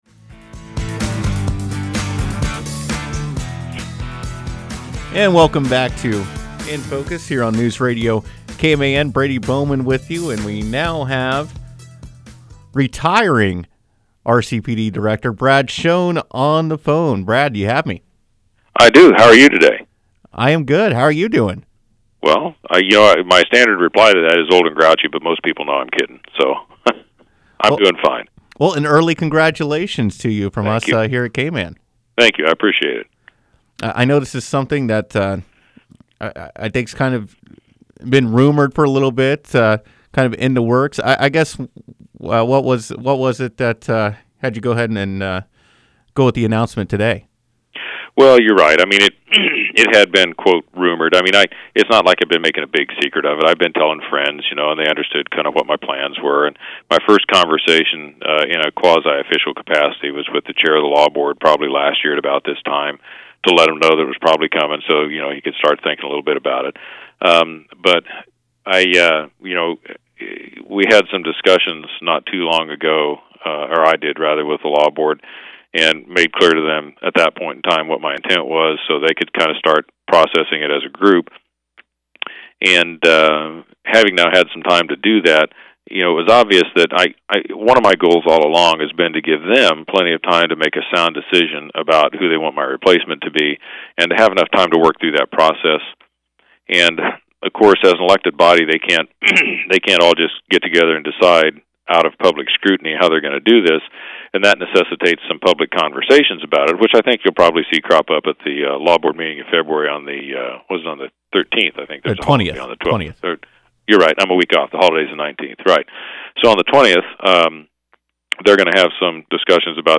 Schoen’s live interview from Friday morning’s edition of KMAN’s “In Focus” can be heard below: